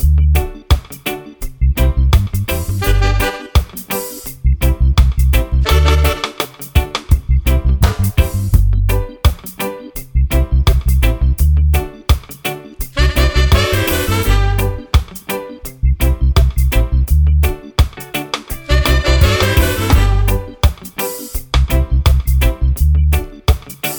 no Backing Vocals Reggae 3:41 Buy £1.50